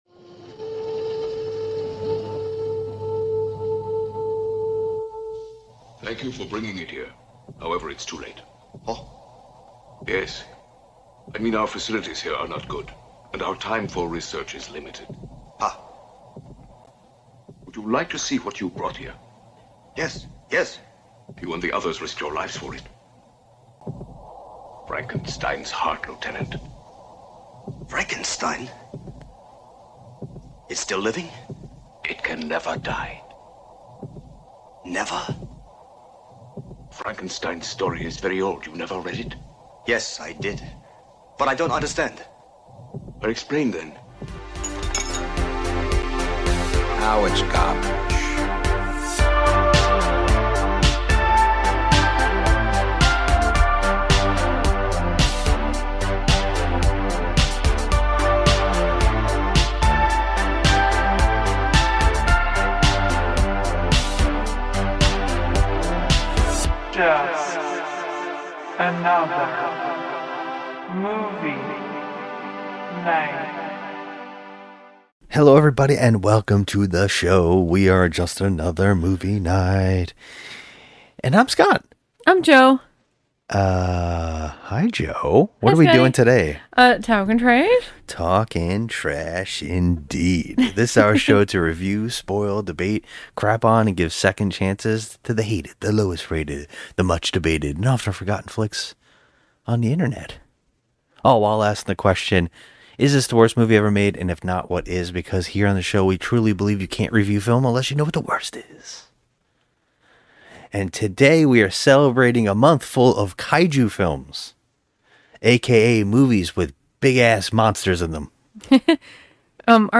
Finally we decided to get off our lazy Asses and start recording our nightly rants, reviews and conversations on all things entertainment (but mainly horror movies).